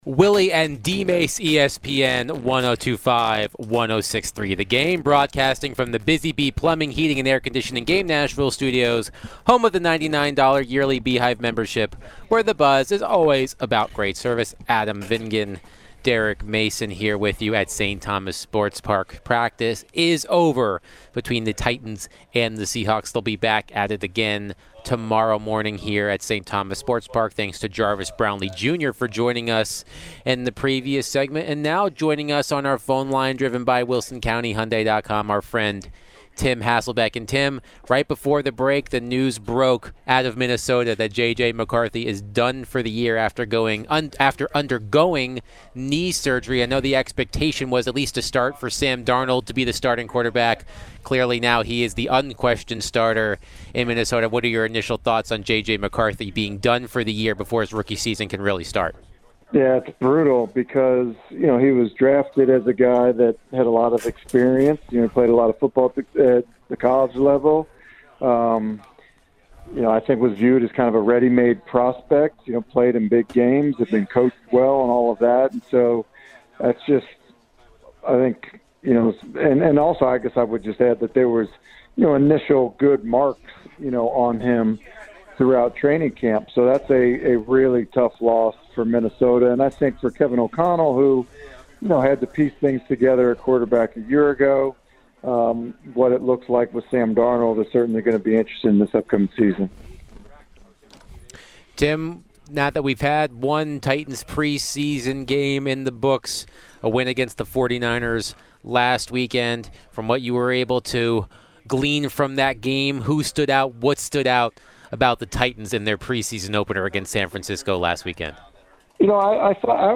The guys chatted with ESPN NFL Analyst Tim Hasselbeck. Tim shared his thoughts about the JJ McCarthy injury news and other rookies from around the league. Later in the conversation, Tim was asked about the Titans and other teams around the league.